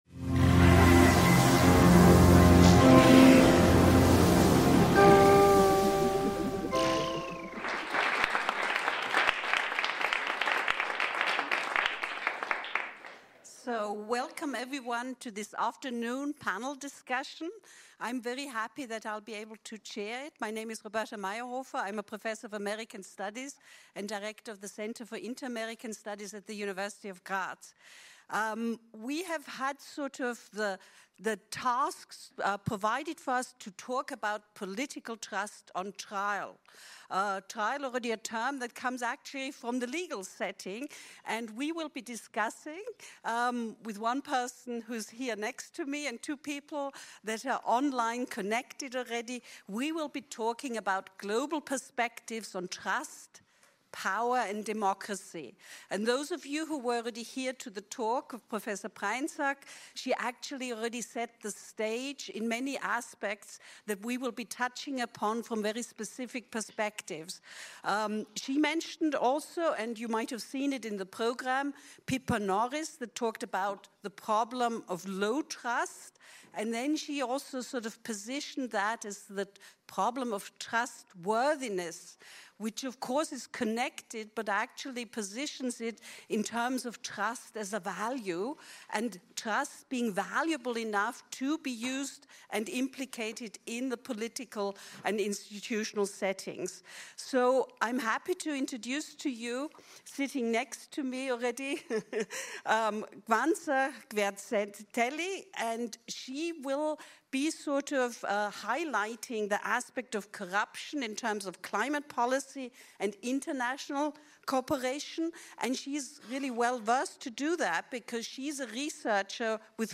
In diesem Panel treffen internationale Perspektiven aufeinander